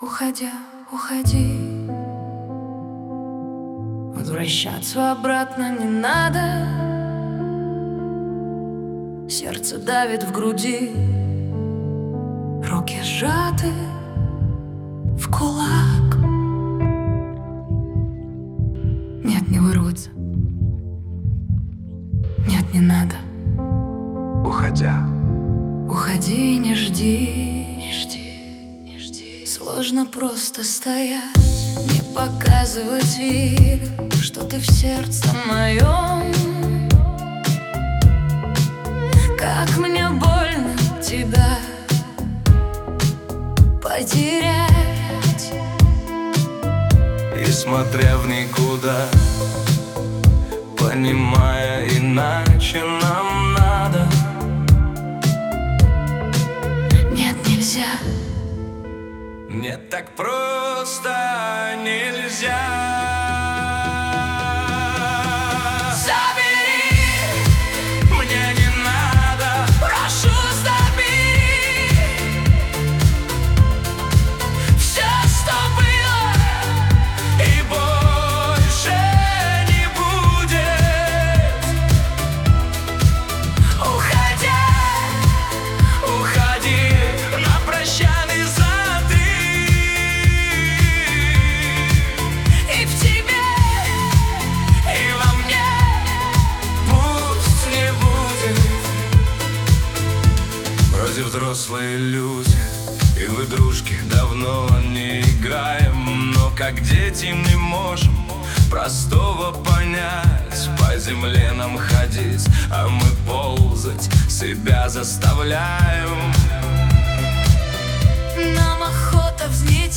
Шансон 2026